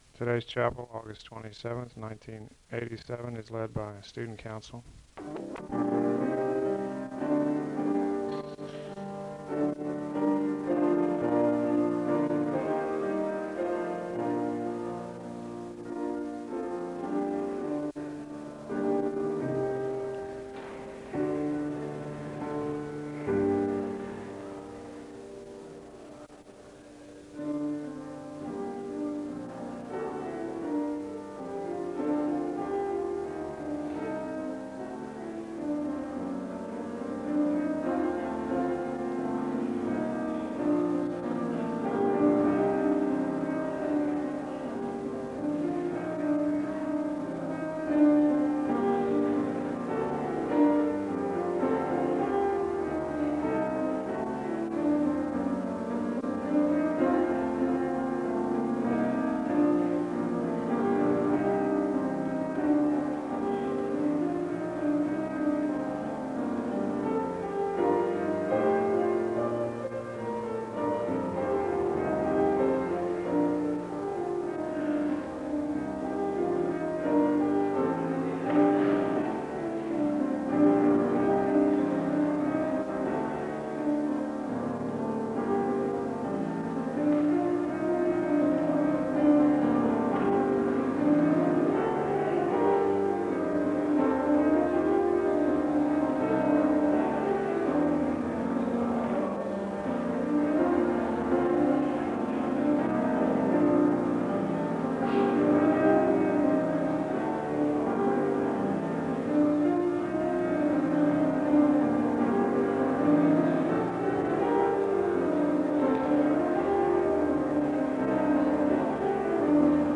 Download .mp3 Description This chapel service is led by the Student Council. The service begins with piano music (0:00-3:46).
The congregation is asked to consider the Word and there is a song of worship (10:24-15:07). The congregation is asked to consider why they are at Southeastern and what brought them there, reading about Paul’s conversion (15:08-24:25). The speaker shares a portion of her testimony (24:26-29:35).